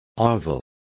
Complete with pronunciation of the translation of ovule.